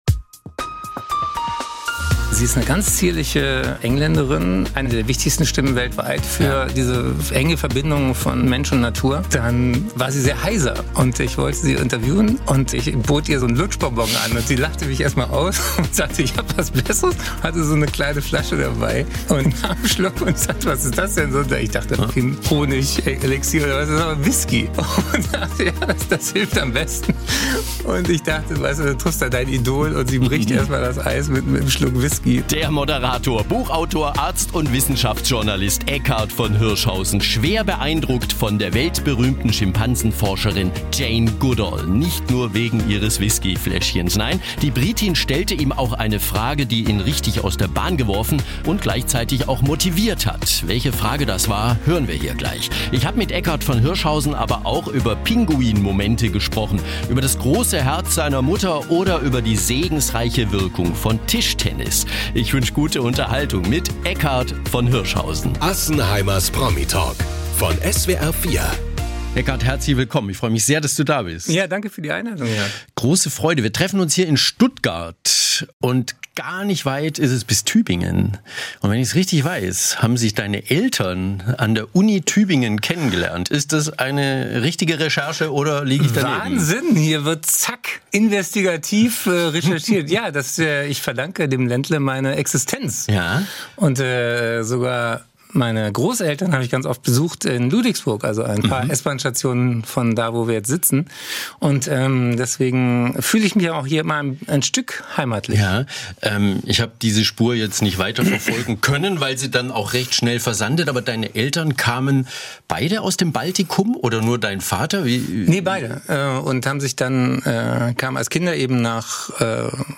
Interview
Studiogäste